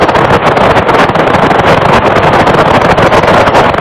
~~040204iob0425sharpsdetail April 2, 2004 Io-B S-bursts 3.6 second extract detail 20.1 MHz 0425:50-54 UT CML III 147.12 Io Phase 085.87 Click below for sound file